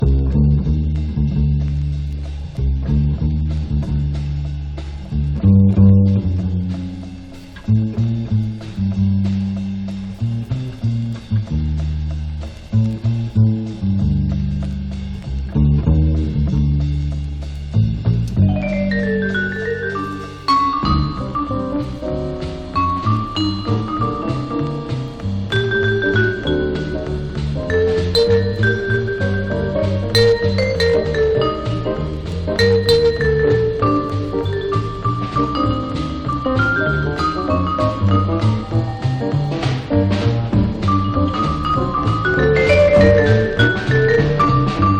Jazz, Swing　USA　12inchレコード　33rpm　Mono
ジャケスレ汚れ　盤スレキズ　盤の材質によるノイズ有